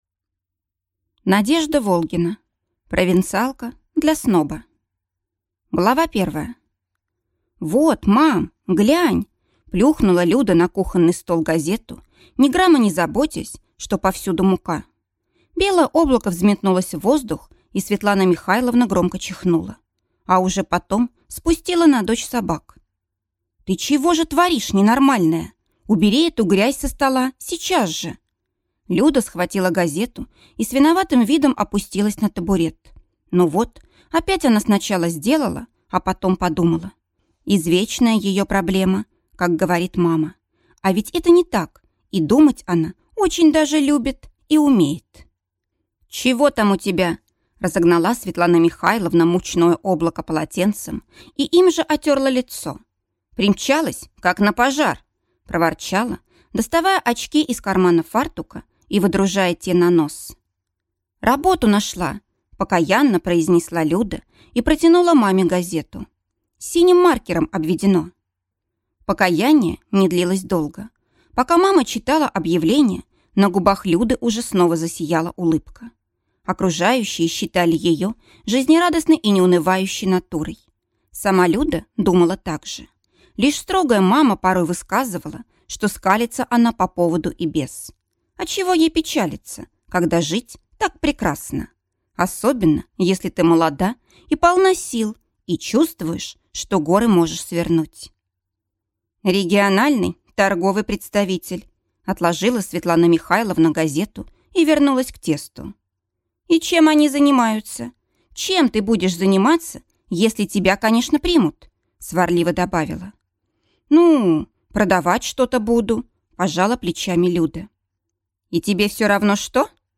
Аудиокнига Провинциалка для сноба | Библиотека аудиокниг